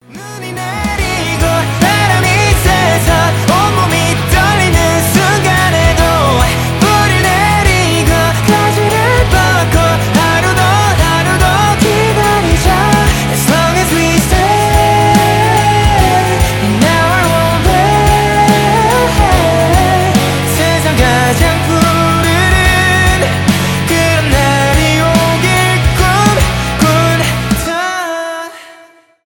баллады
k-pop
pop rock